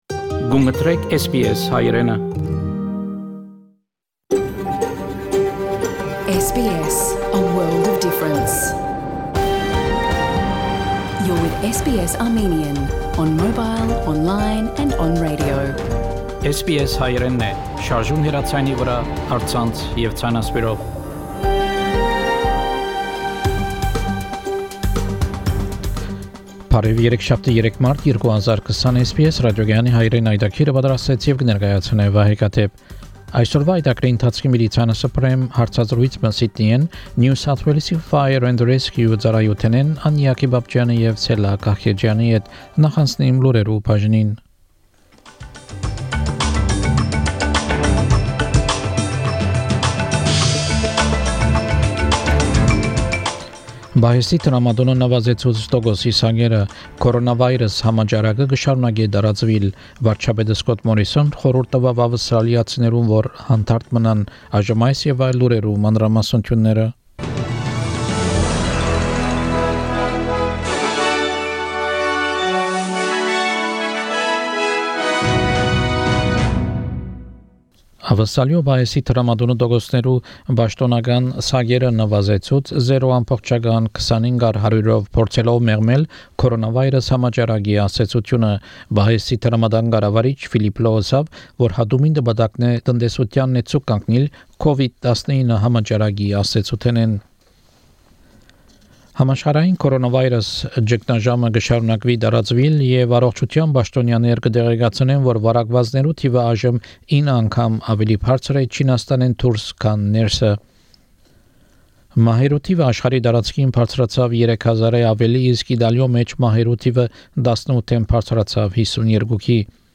Armenian news bulletin - March 3